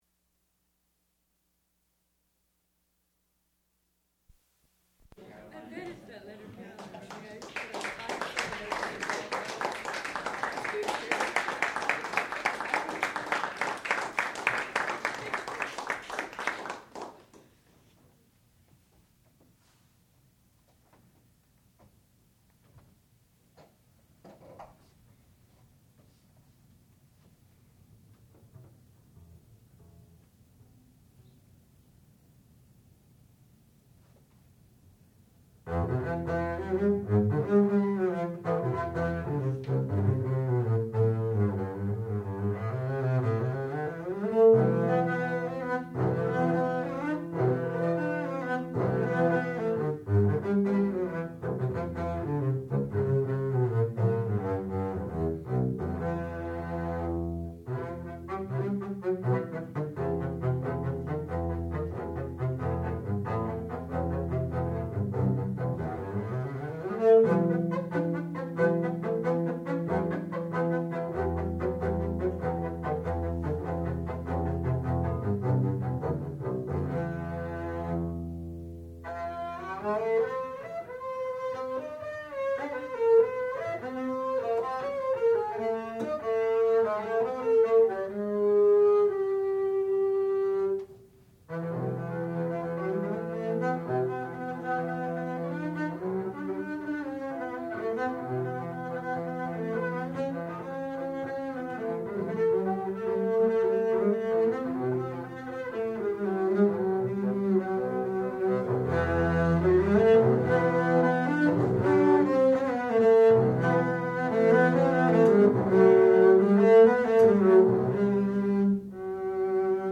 sound recording-musical
classical music
double bass
Qualifying Recital